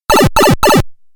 tuyau.mp3